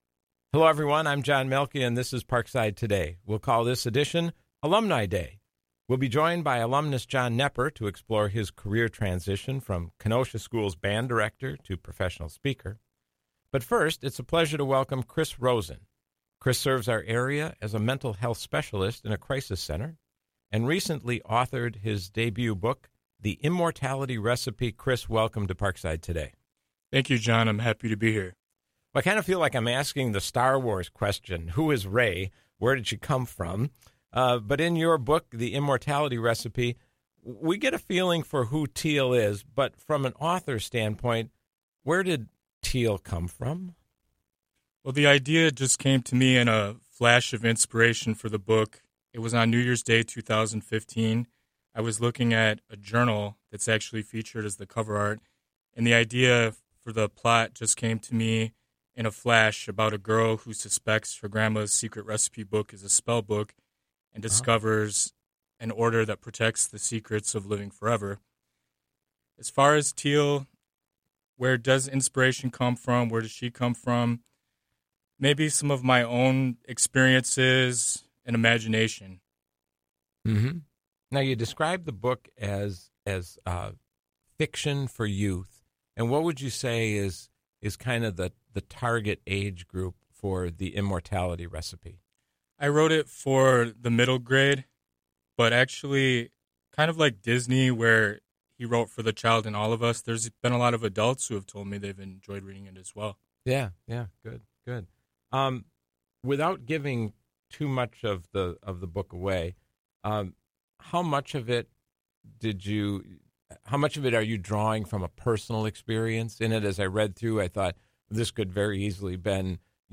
This show originally aired on Tuesday, February 18, at 4 p.m. on WIPZ 101.5 FM.